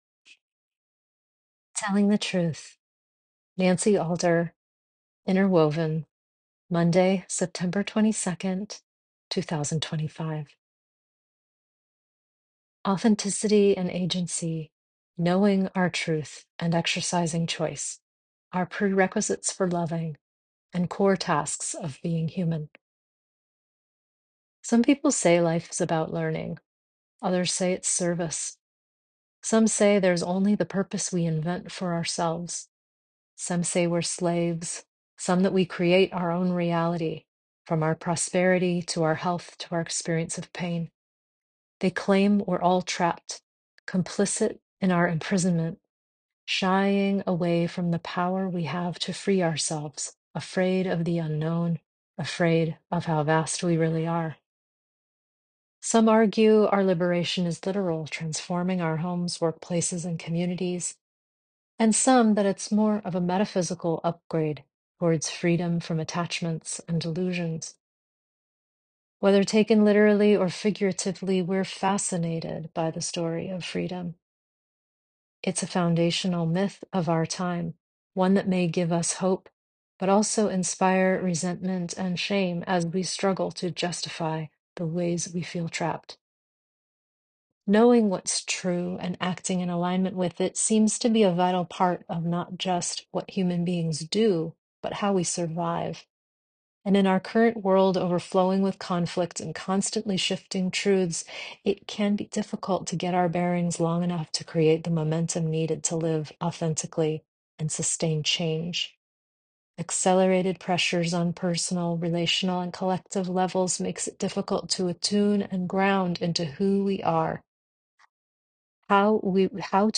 Enjoy this 9 1/2 minute read or let me read it to you via the audio file here.